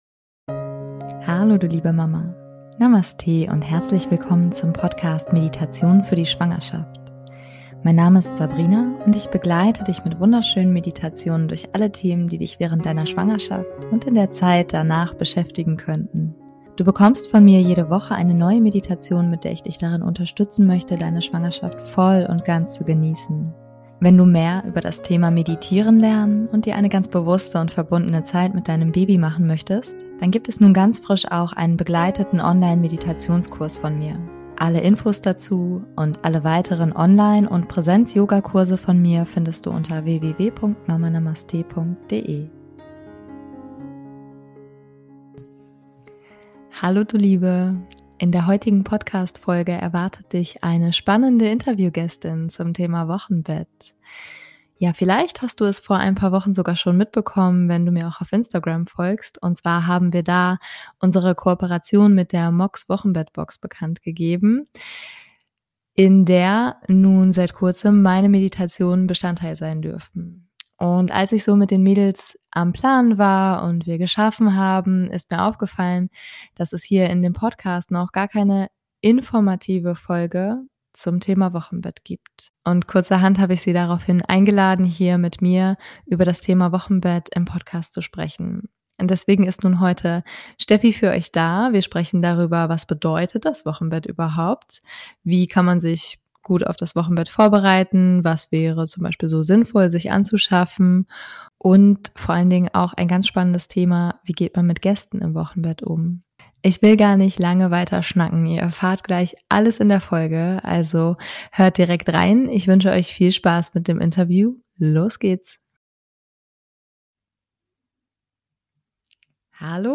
#090 - Wochenbett Vorbereitung | Tipps für das Wochenbett - Interview